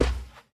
Minecraft Version Minecraft Version snapshot Latest Release | Latest Snapshot snapshot / assets / minecraft / sounds / mob / panda / step2.ogg Compare With Compare With Latest Release | Latest Snapshot